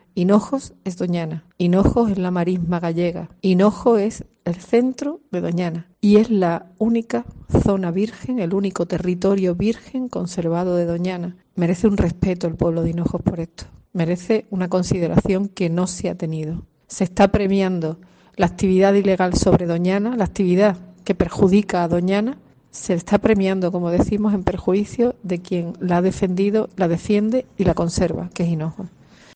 Joaquina del Valle, alcaldesa de Hinojos